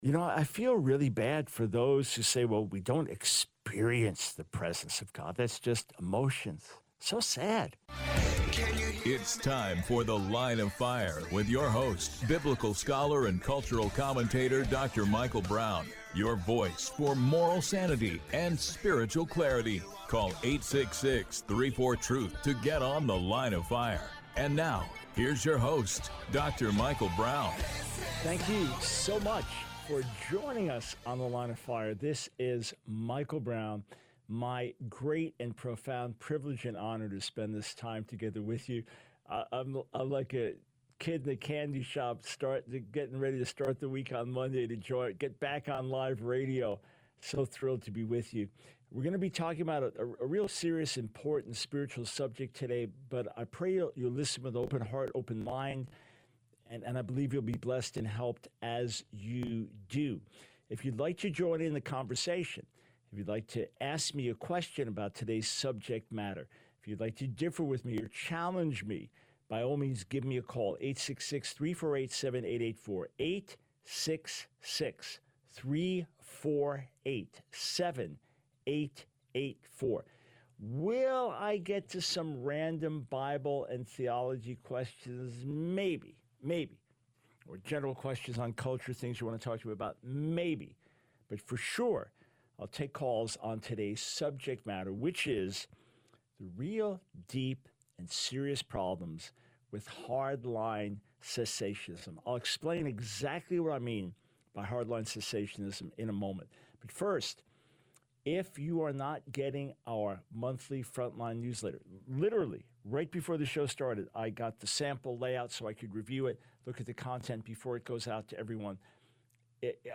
The Line of Fire Radio Broadcast for 08/12/24.